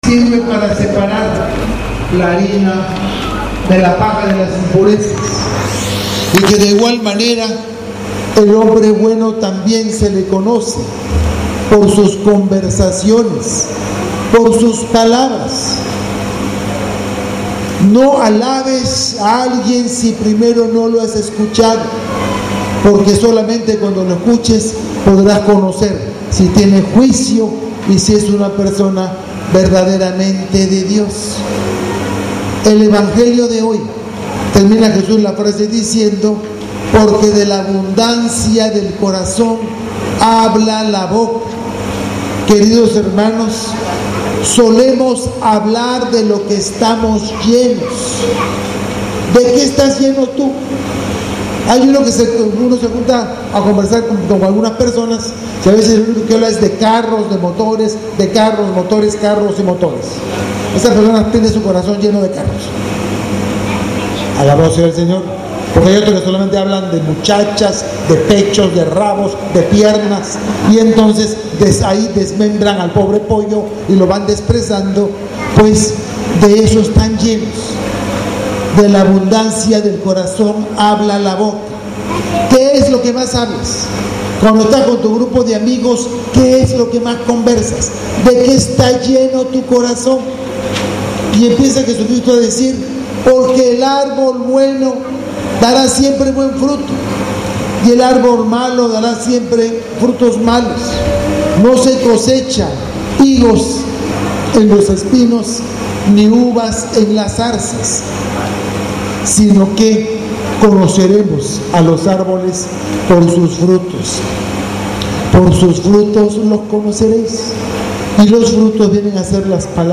Homilia del Domingo 03/03/19